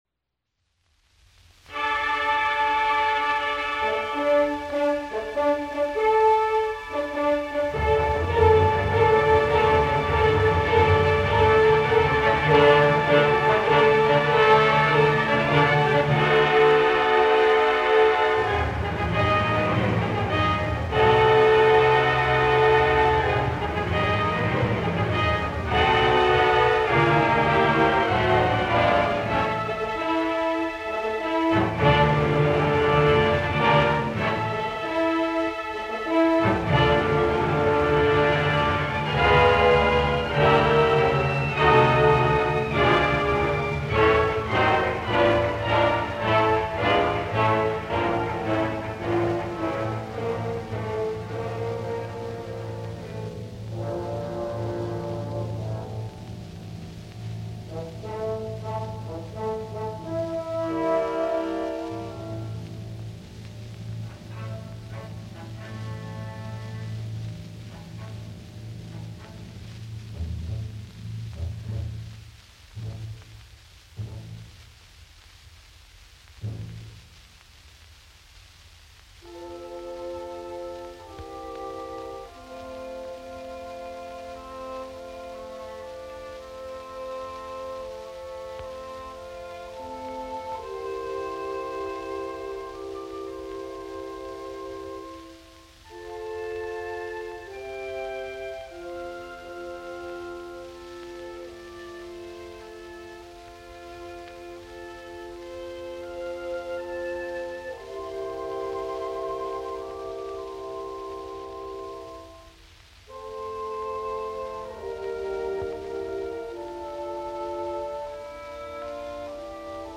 Wagner – Der Fliegende Holländer (Overture) New York Philharmonic Orchestra
Gramophone recording